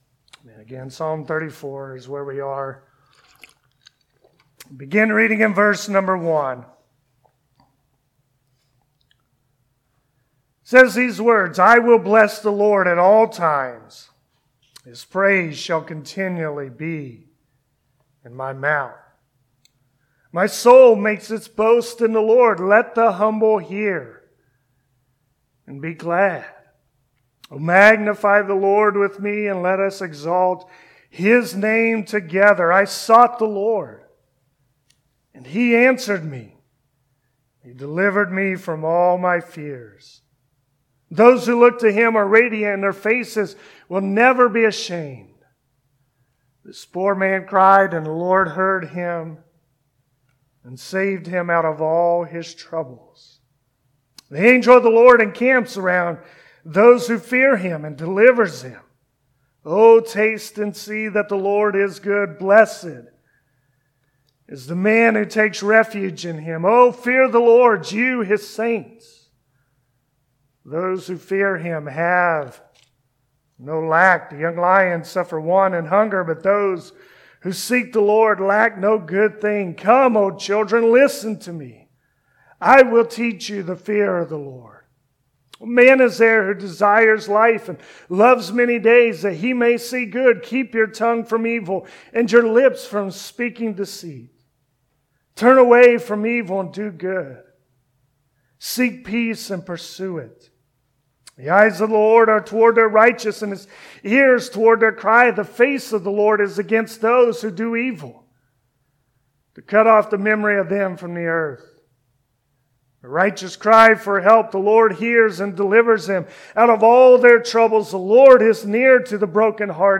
Passage: Psalm 34 Service Type: Sunday Morning Psalm 34.